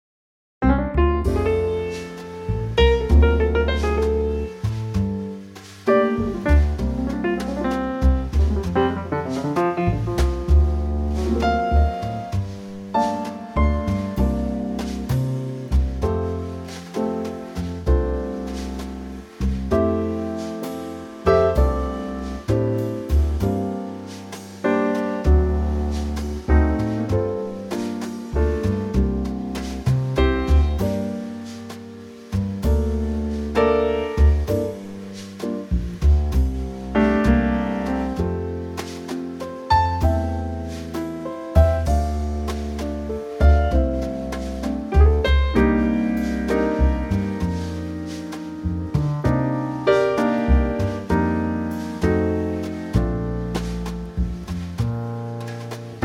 Unique Backing Tracks
key - F to G - vocal range - E to E
Trio arrangement
in a slow 4's blues feel.